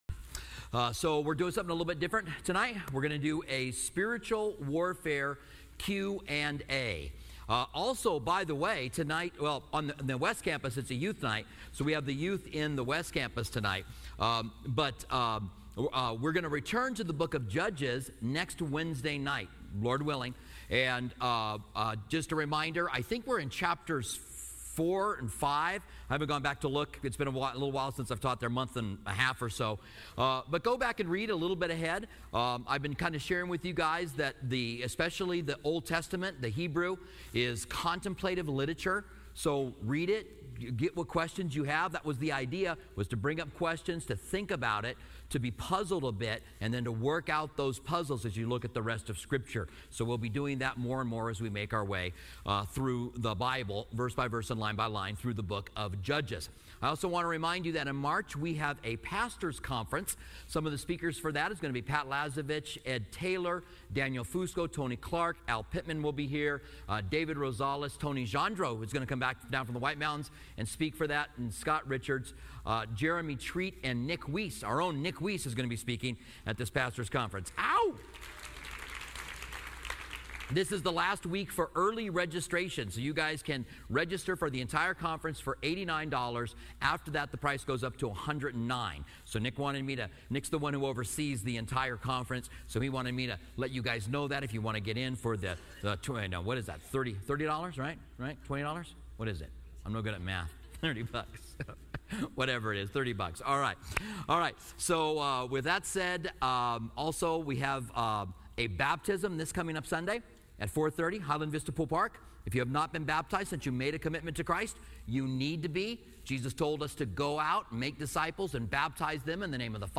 Spiritual Warfare Q&A